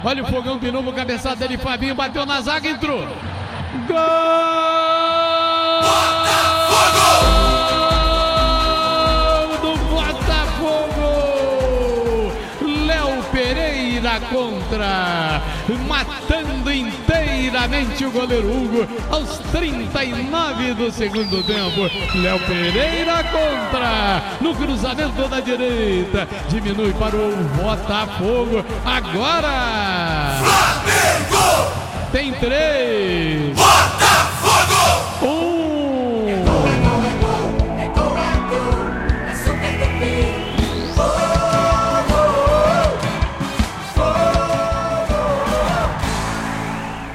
Ouça os gols da vitória do Flamengo sobre o Botafogo pelo Carioca com a narração do Garotinho